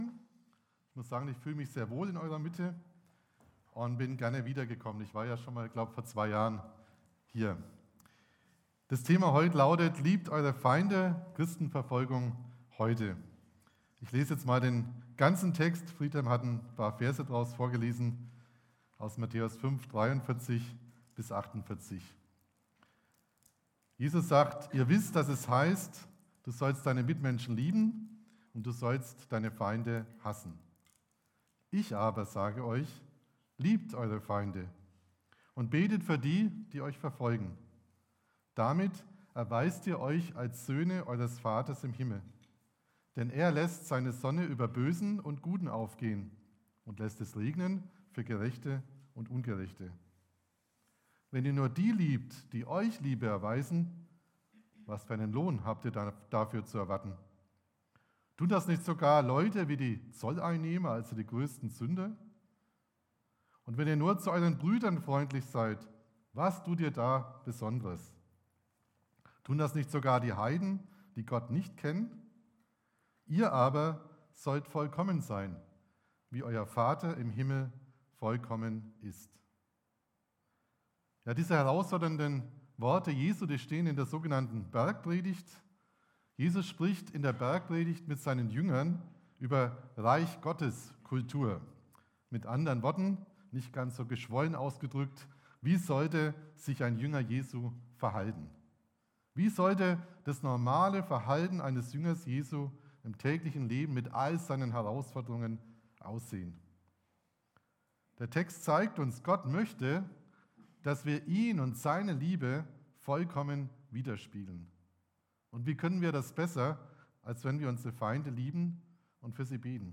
Gottesdienst am 26.11.2023